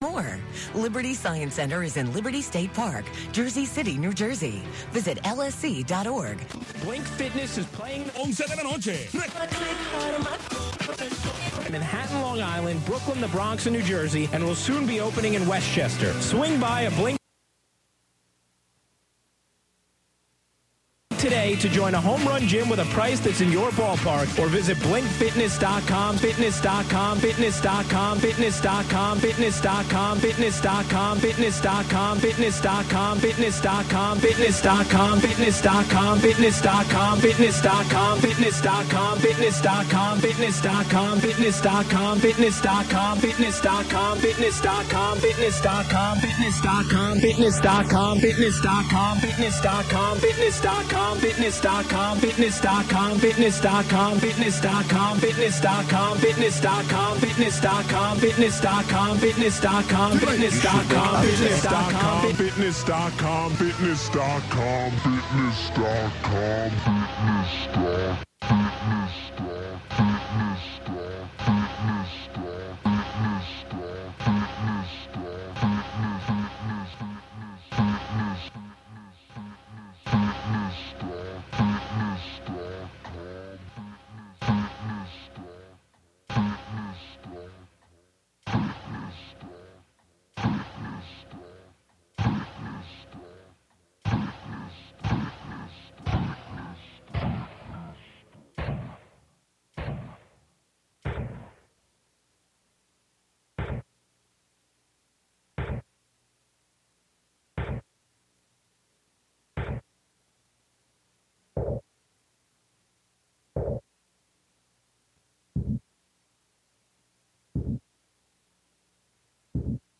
(28:05) Live remix of NYC radio.